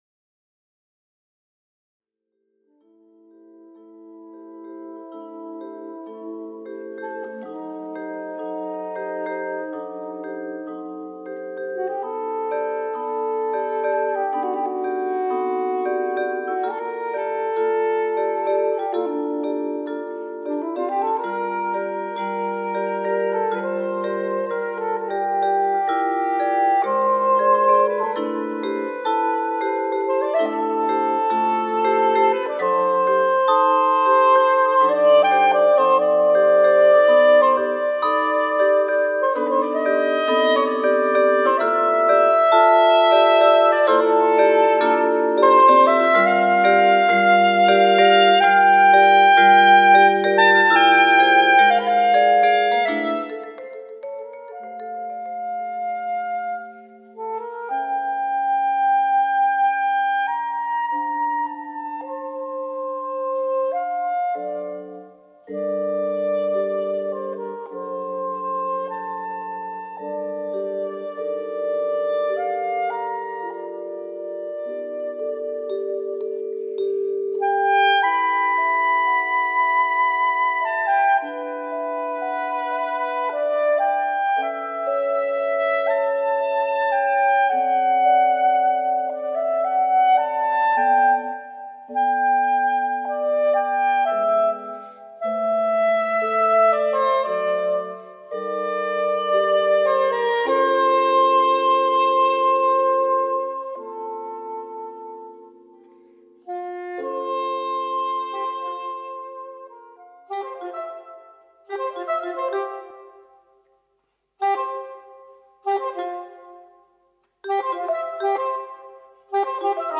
Soprano and Alto Saxophones
Piano